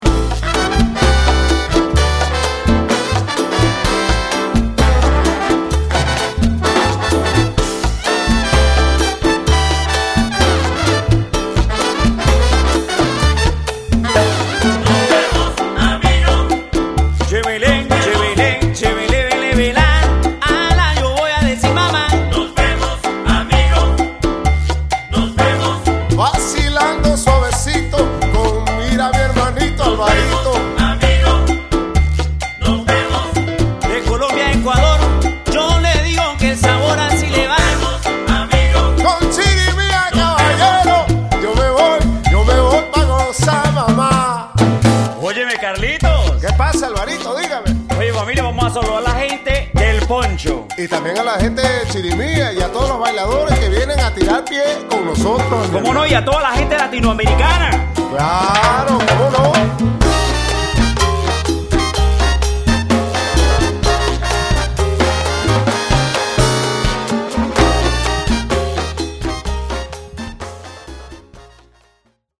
orchestra di salsa, merengue, boleros, cumbia
voce e percussioni
piano
batteria e timbales
congas
tromba
sax tenore